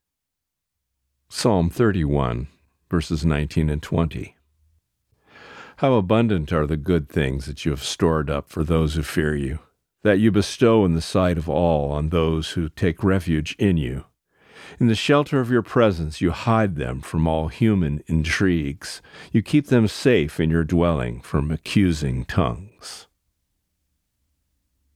Reading: Psalm 31:19-20